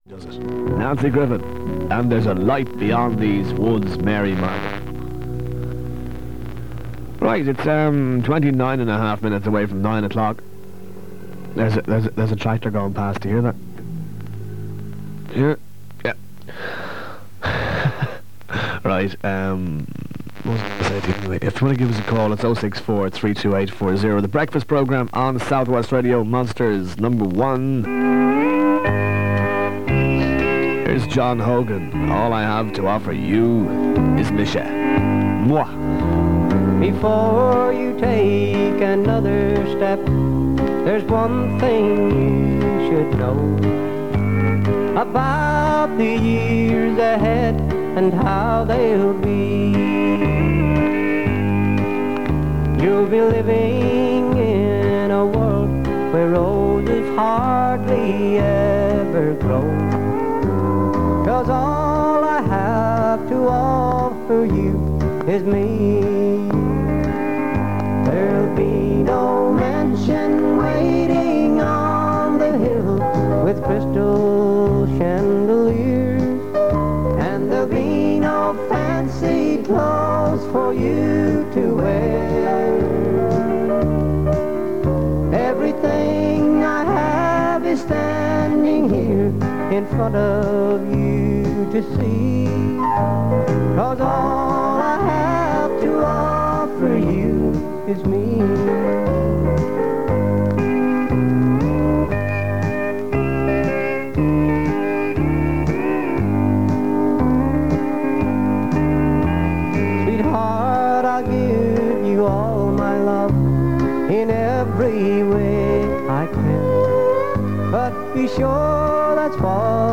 Adverts are heard for businesses in Kerry and north Cork, including one for the Radio Mallow music awards. There’s also a call from a listener who plays the tin whistle live on air and in keeping with some other rural stations, the Angelus bell is broadcast at midday.